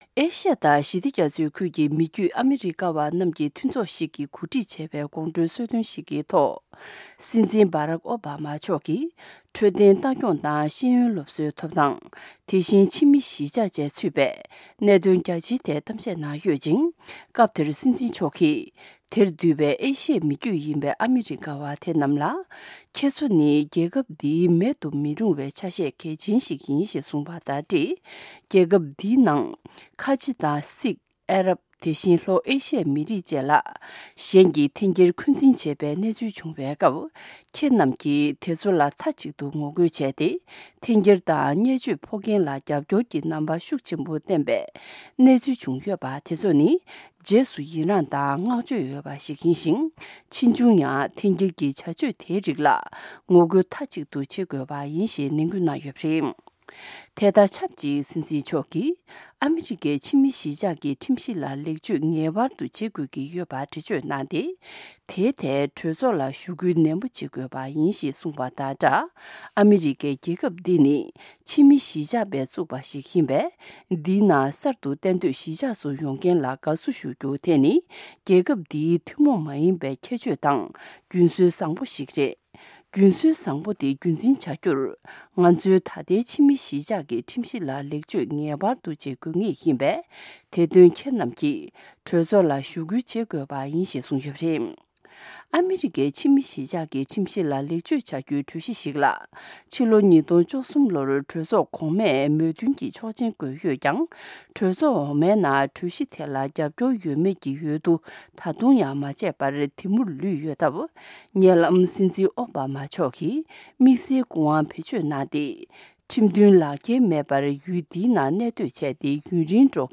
ཨེ་ཤི་ཡ་དང་ཞི་བདེ་རྒྱ་མཚོའི་ཁུལ་གྱི་མི་རྒྱུད་ཨ་མི་རི་ཀ་བ་རྣམས་ཀྱི་མཐུན་ཚོགས་ཤིག་གིས་གོ་སྒྲིག་བྱས་པའི་དགོང་དྲོའི་གསོལ་སྟོན་ཞིག་གི་ཐོག་ སྲིད་འཛིན་བྷ་རག་ཨཽ་བྷ་མ་མཆོག་གིས་ འཕྲོད་སྟེན་ལྟ་སྐྱོང་དང་ ཤེས་ཡོན་སློབ་གསོའི་ཐོབ་ཐང་ དེ་བཞིན་ ཕྱི་མི་གཞིས་ཆགས་བཅས་ཚུད་པའི་གནད་དོན་རྒྱ་ཆེའི་ཐད་གཏམ་བཤད་གནང་ཡོད་ཅིང་ སྐབས་དེར་སྲིད་འཛིན་མཆོག་གིས་དེར་འདུས་པའི་ཨེ་ཤི་ཡེའི་མི་རྒྱུད་ཡིན་པའི་ཨ་མི་རི་ཀ་བ་དེ་རྣམས་ལ་ ཁྱེད་ཚོ་ནི་ རྒྱལ་ཁབ་འདིའི་མེད་དུ་མི་རུང་བའི་ཆ་ཤས་གལ་ཆེན་ཞིག